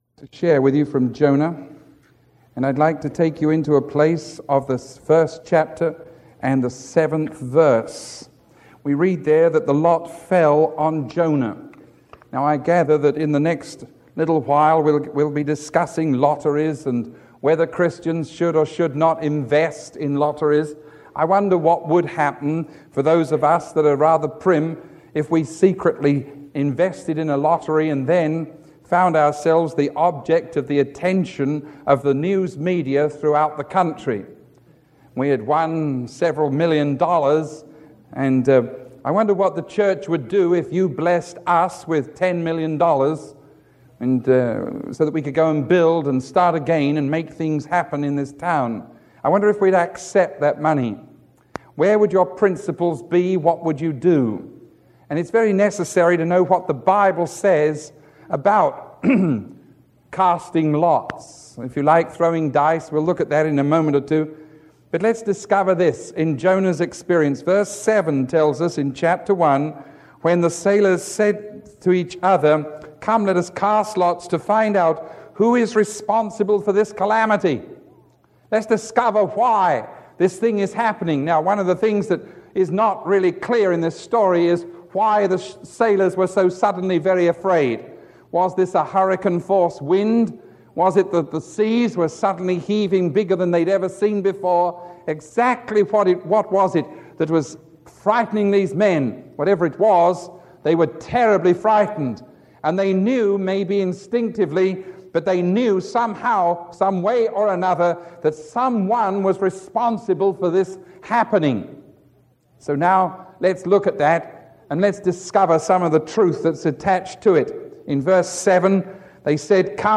Sermon 0955A recorded on December 9